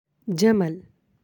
(jamal)